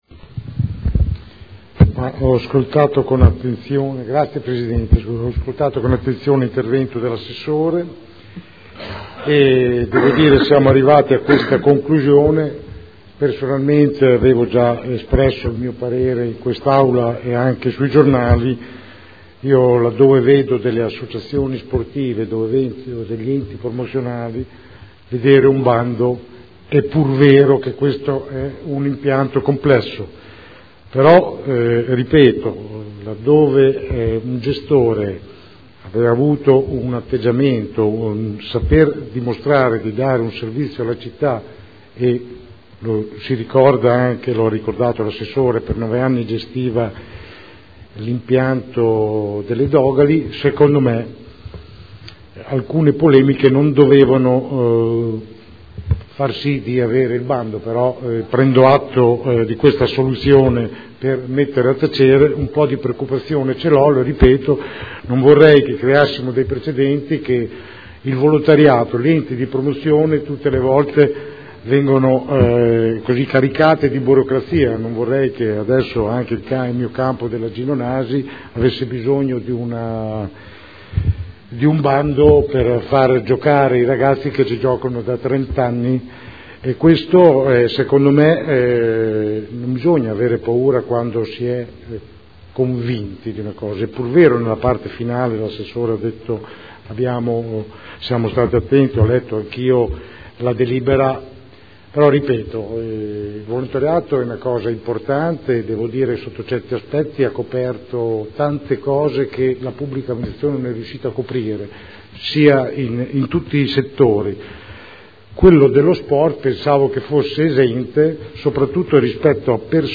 Seduta del 16 gennaio. Proposta di deliberazione: Concessione gestione piscina Dogali: indirizzi. Dibattito